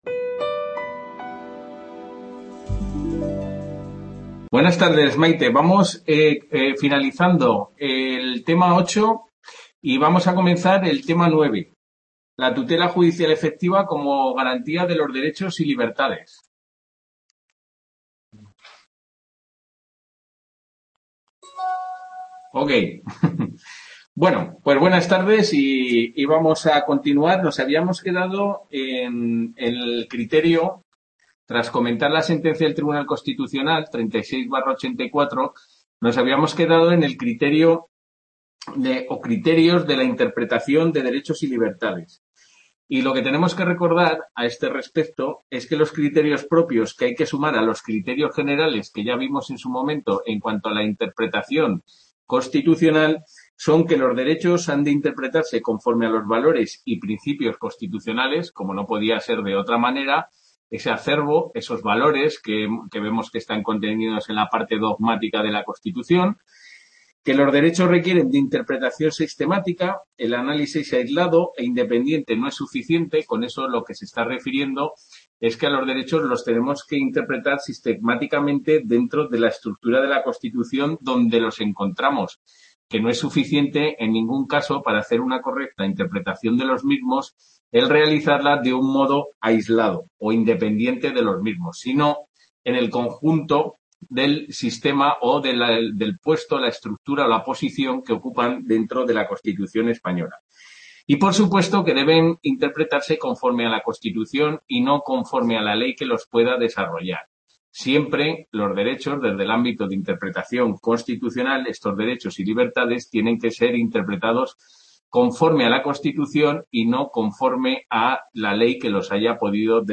TUTORIA DCHO CONSTITUCIONAL II | Repositorio Digital